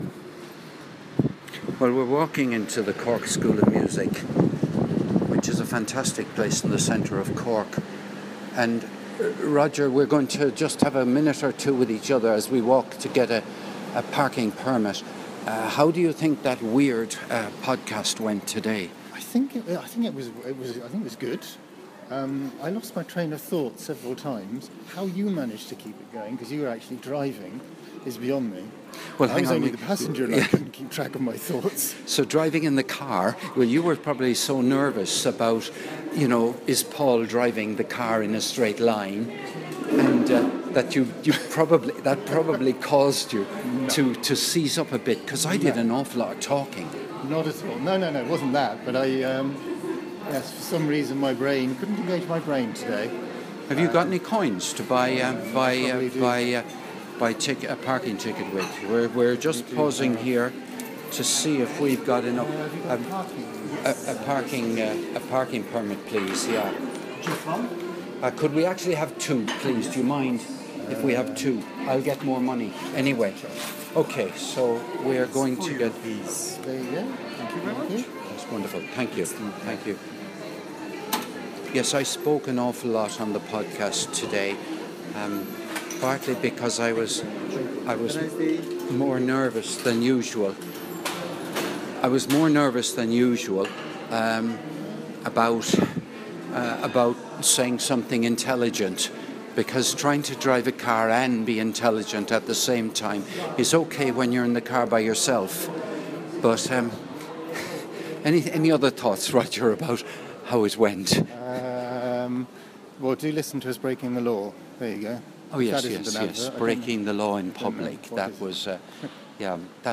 This was recorded while showering on the morning of Saturday 22nd of February 2025